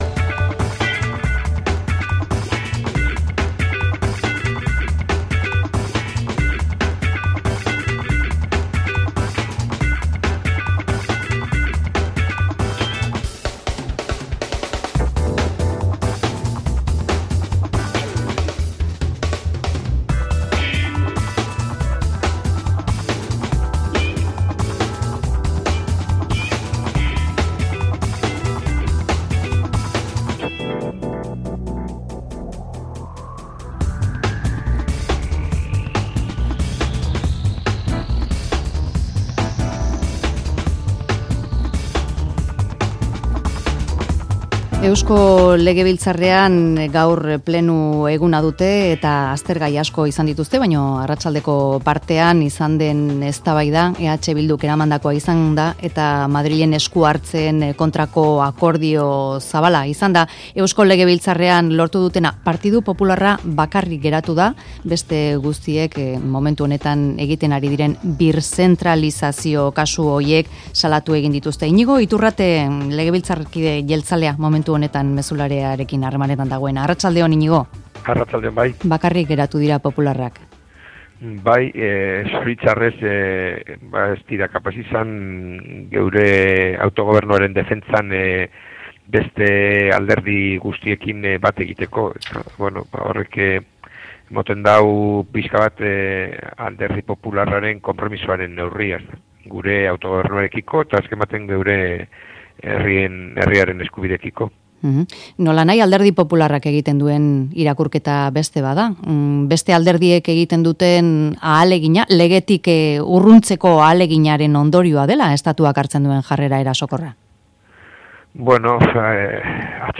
EH Bilduk aurkeztutako ekimenarekin bat egin dute EAJk, PSE-EEk eta Elkarrekin Podemosek. Iñigo Iturrate jeltzalea izan da Mezularian.